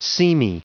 Prononciation du mot seamy en anglais (fichier audio)
Prononciation du mot : seamy